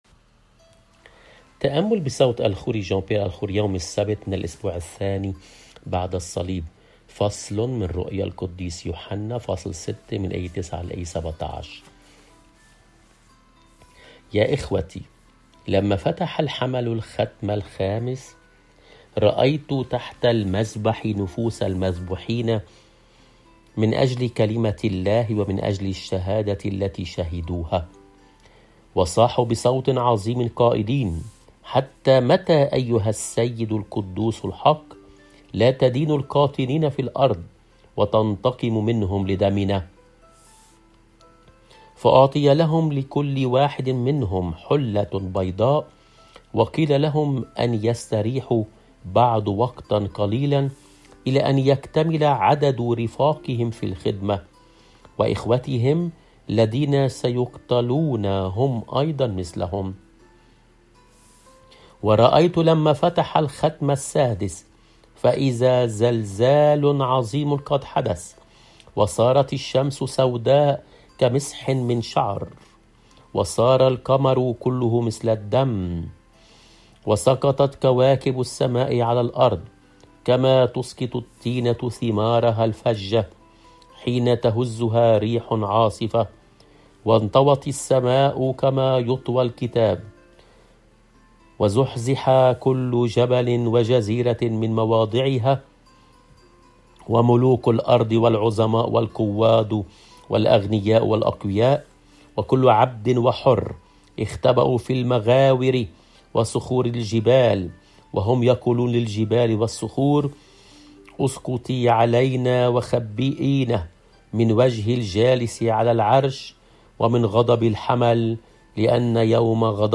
الرسالة